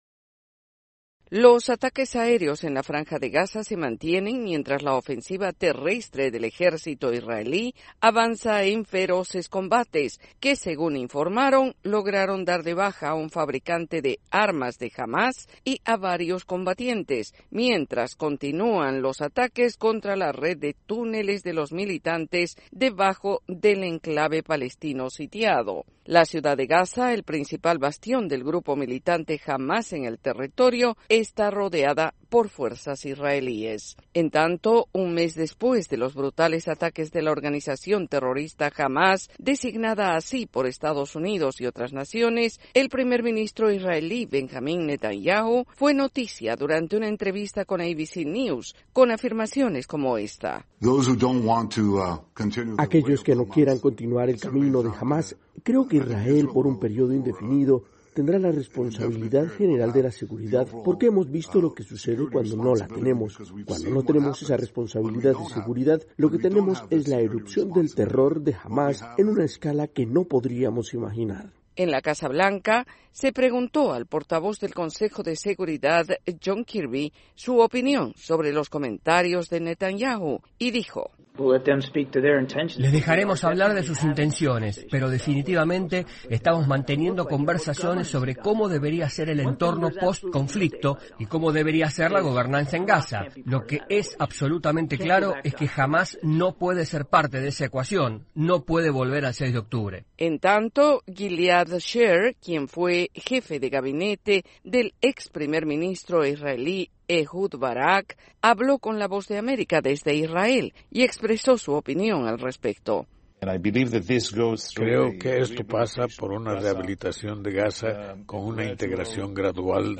reporte